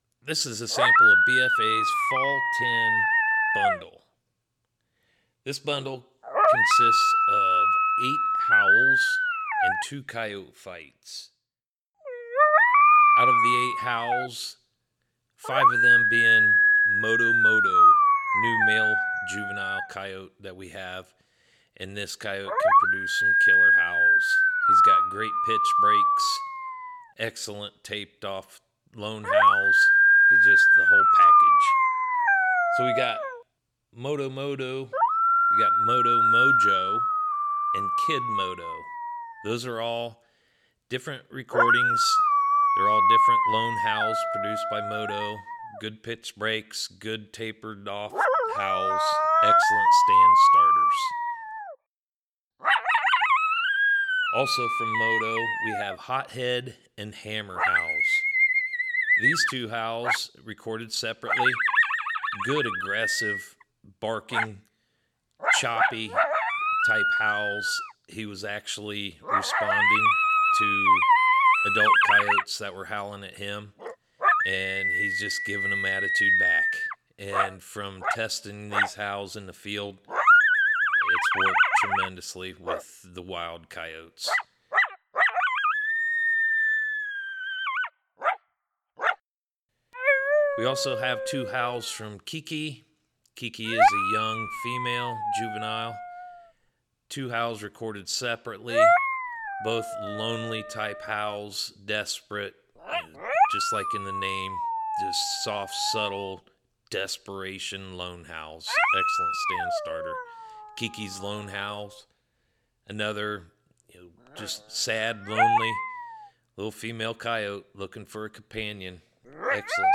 Fall Coyote Vocals
The bundle includes 7 Single howls, 1 Coyote Pair Howl and 2 Coyote Fights.
BFA Fall Coyote Vocal Bundle Sample.mp3